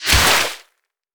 water_spell_impact_hit_02.wav